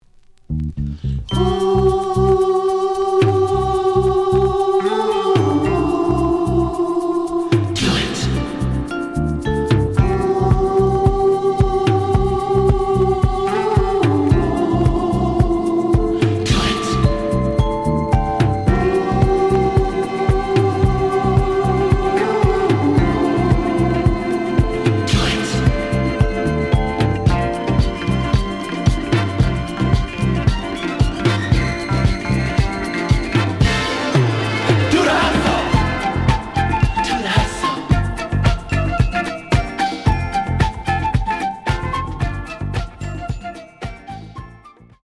試聴は実際のレコードから録音しています。
●Genre: Disco